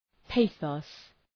{‘peıɵɒs}
pathos.mp3